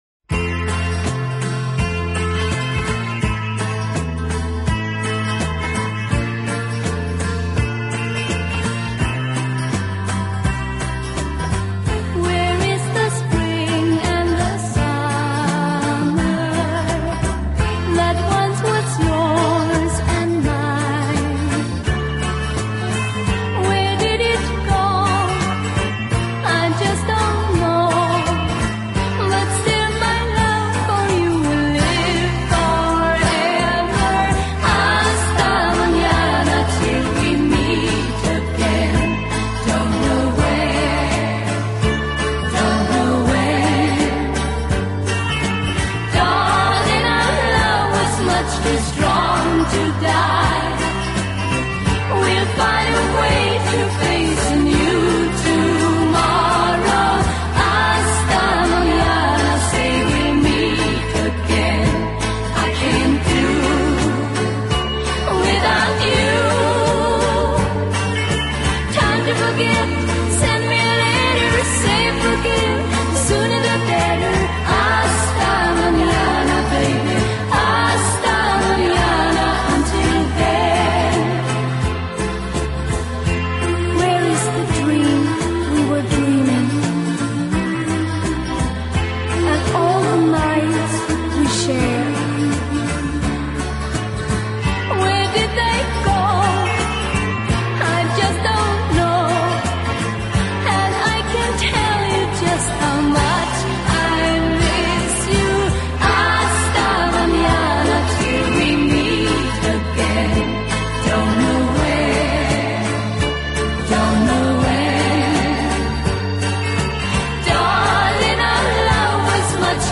音乐风格： 流行|流行/摇滚|(Pop/Rock)